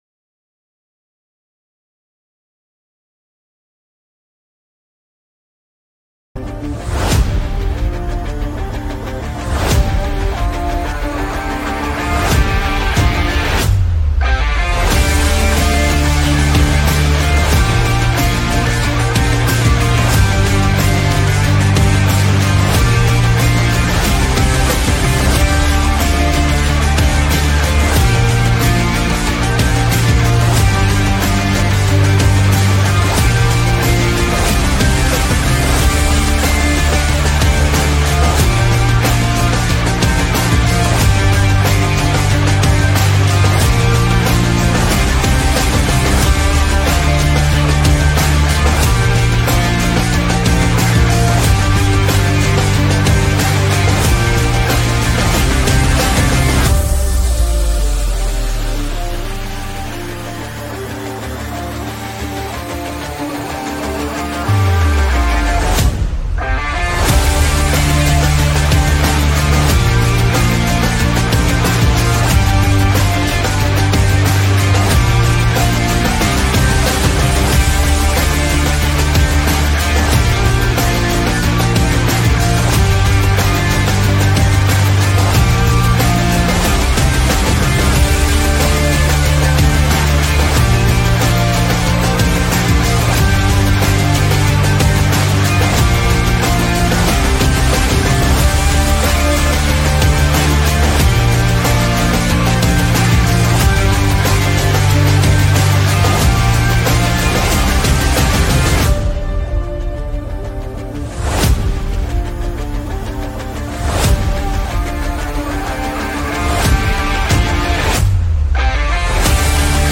Live Interview with BKFC Fighter